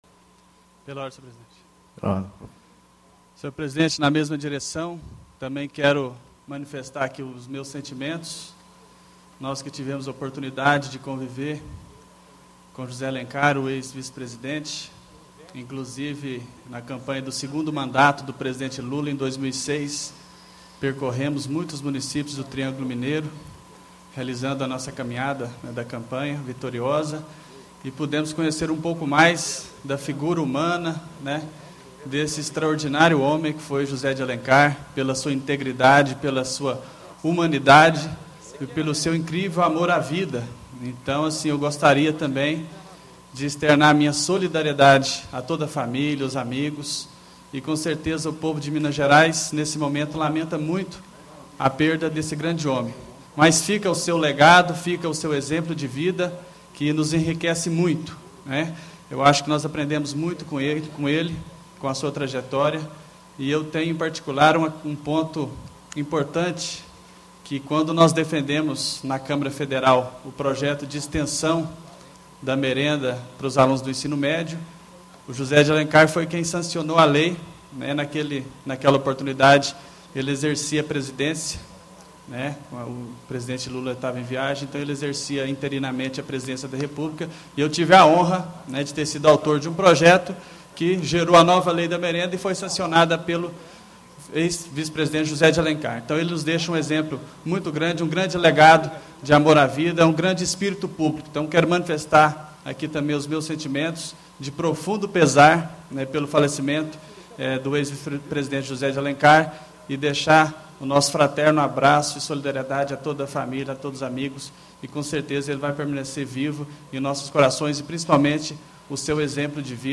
Reunião de Plenário - Pronunciamento sobre o ex-vice-presidente da república, José Alencar - Assembleia Legislativa de Minas Gerais